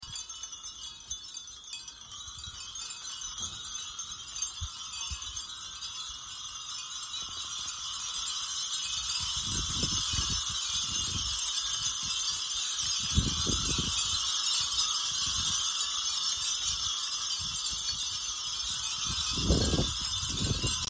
説明では、僧侶がひとつひとつ手塗したカラフルなガラス風鈴など4,000個が奉納され、風が吹くと風鈴が一斉に鳴り響き、境内が涼しげな音色で満たされる『風鈴参道通り抜け』が開催されますとの事。
fuurin.mp3